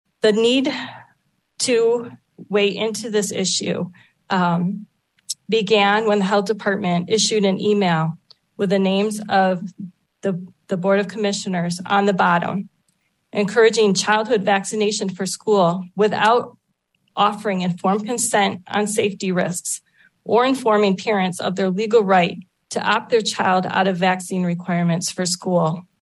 During Tuesday night’s biweekly business meeting, the panel considered a resolution affirming the rights of parents and guardians to not have their school aged children vaccinated, emphasizing individual freedoms and rights of parents, and recommending that information on vaccines for schools and childcare programs include that of available exemptions and waivers from requirements. Board vice chairwoman Sylvia Rhodea of Allendale.